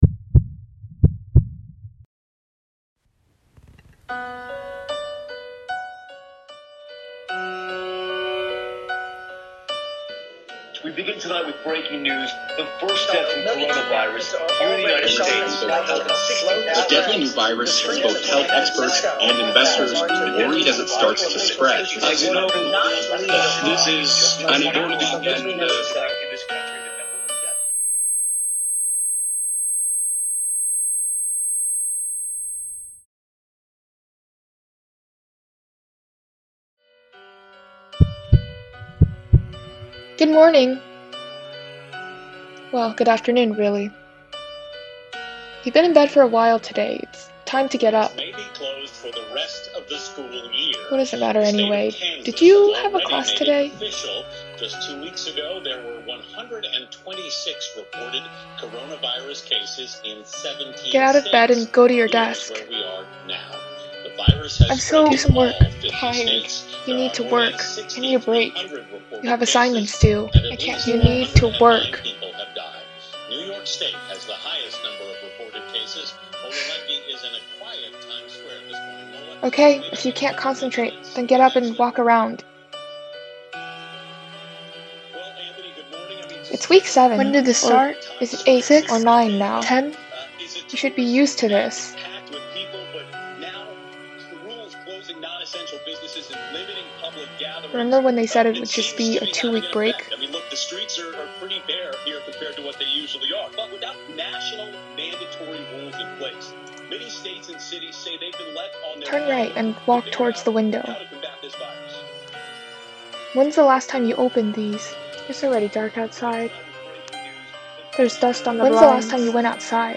Audio Walk
• Warning: there are two incidents of high pitched noises that may cause some listeners discomfort at 0:25-0:33 and 4:53-4:59.
• Credit to Freesound and Storyblocks for background music, heartbeat sounds, and ambient noises